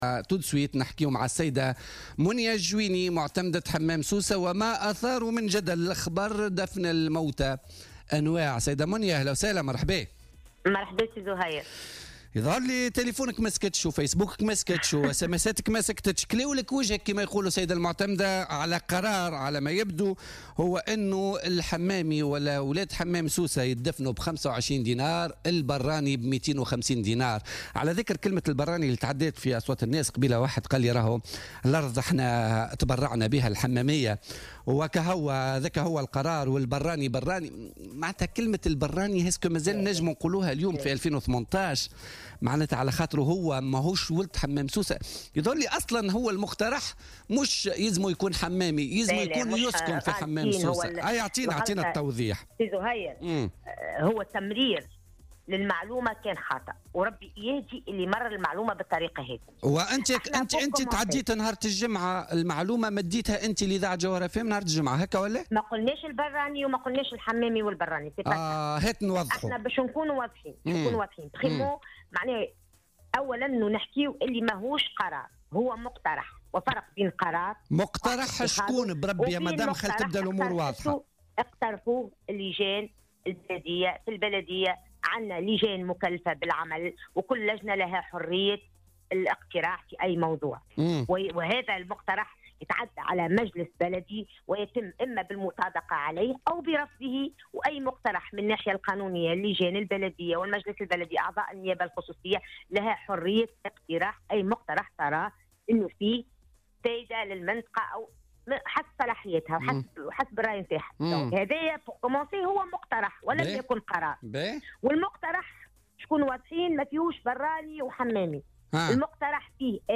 أعلنت معتمدة حمام سوسة منية الجويني، خلال مداخلة هاتفية لها في برنامج "بوليتيكا" اليوم الاثنين 5 مارس 2018، عن إلغاء مقترح مشروع قانون ينص على دفن أموات جهة حمام سوسة ب25 دينارا، مقابل 250 دينارا لدفن الأموات الذين لا يقطنون في الجهة.